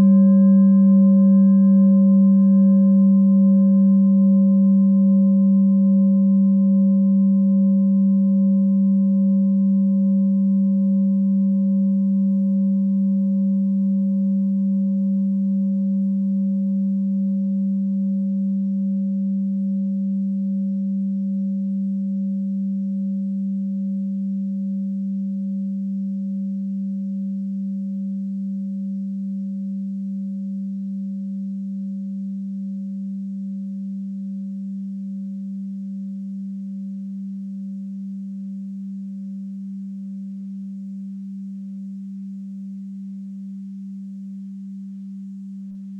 Tibet Klangschale Nr.19
Sie ist neu und wurde gezielt nach altem 7-Metalle-Rezept in Handarbeit gezogen und gehämmert.
Hörprobe der Klangschale
(Ermittelt mit dem Filzklöppel)
Klangschalen-Gewicht: 1030g
Klangschalen-Öffnung: 19,4cm
klangschale-tibet-19.wav